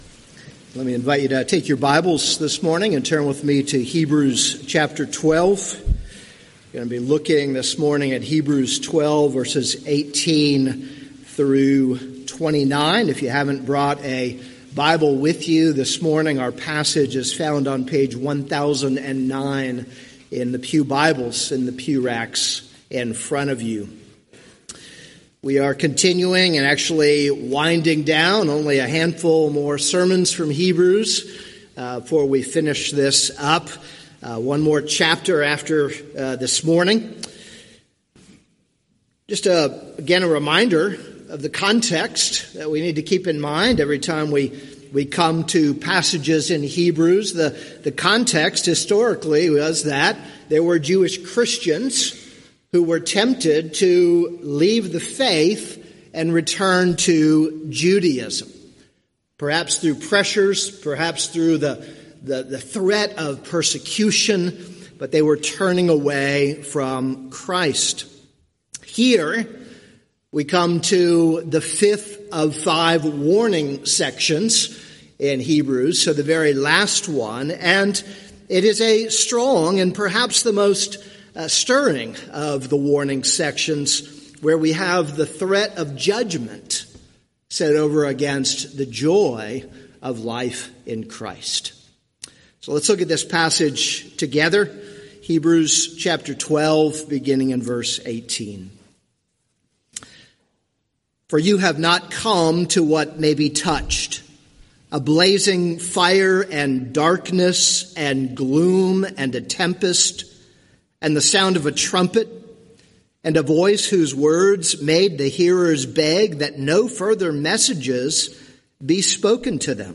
This is a sermon on Hebrews 12:18-29.